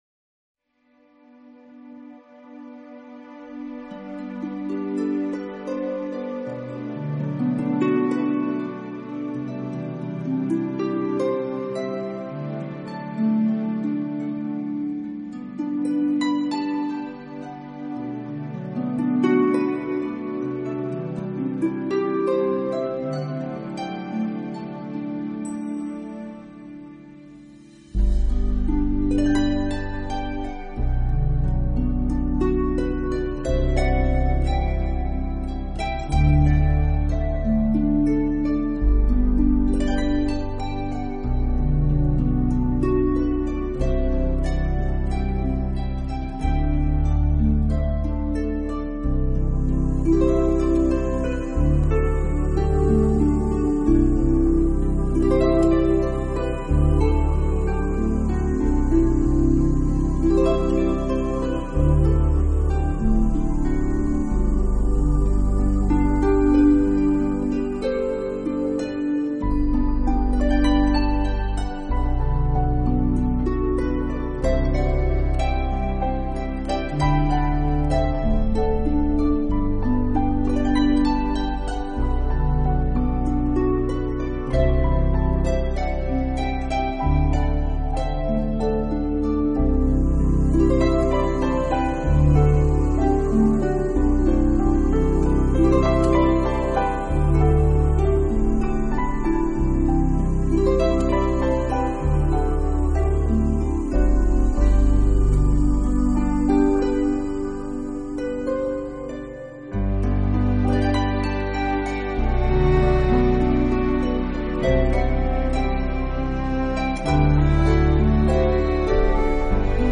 音乐的清澈度和纯净度却是非凡的，竖琴的演奏，没有过多的其他乐器
偶尔零星的乐器的烘托却让单纯的旋律音乐更有高潮和震撼力！